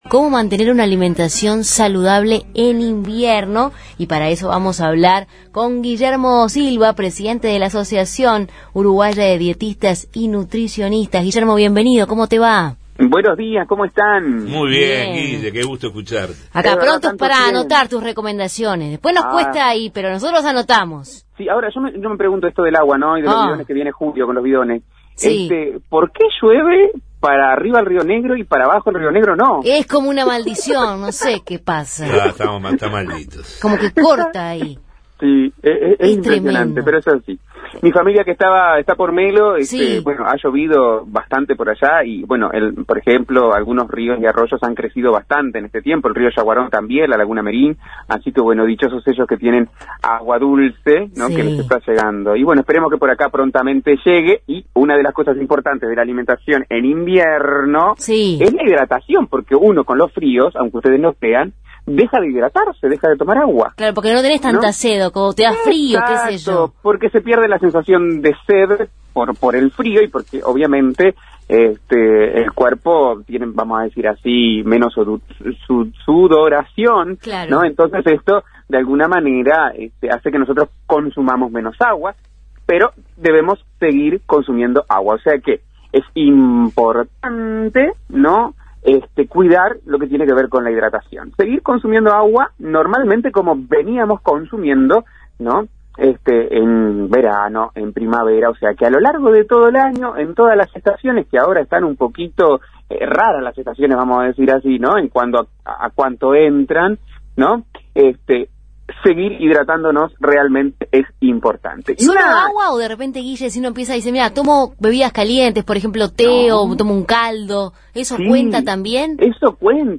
Columna de la Asociación Uruguaya de Dietistas y Nutricionistas (Audyn)